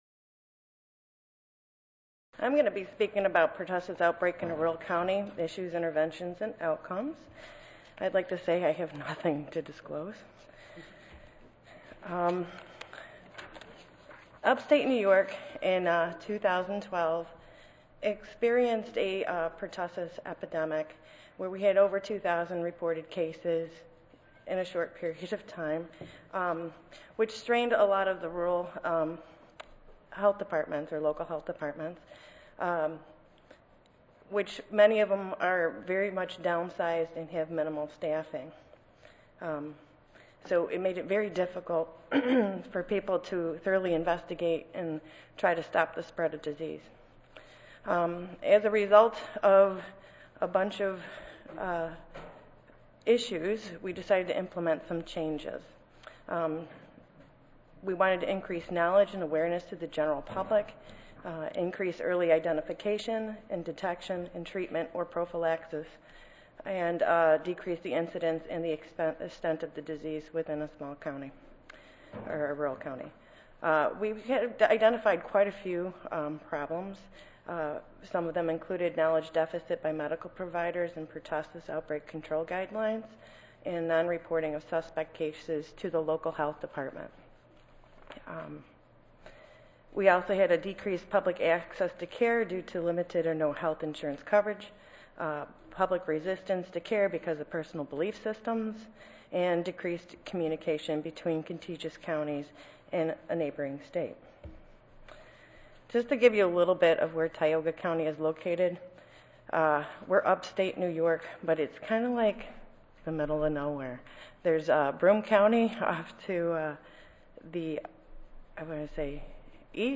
141st APHA Annual Meeting and Exposition (November 2 - November 6, 2013): Public Health Management of Disasters and Immunizations